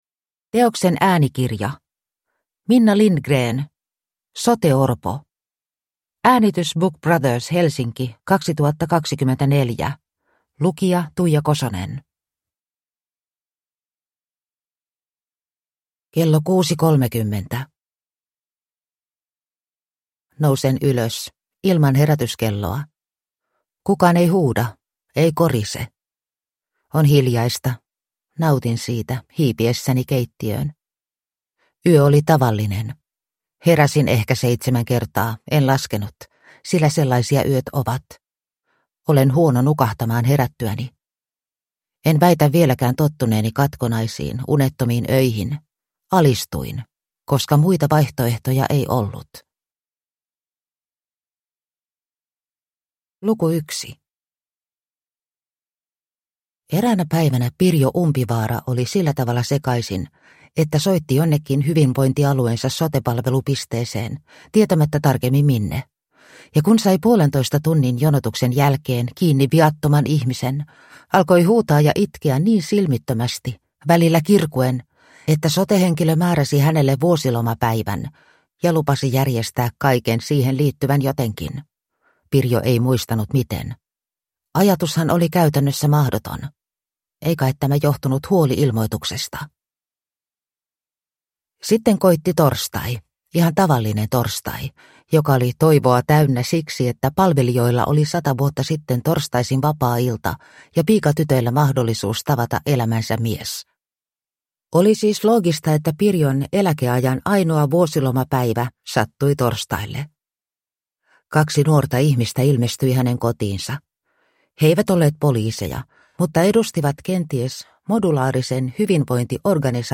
Soteorpo – Ljudbok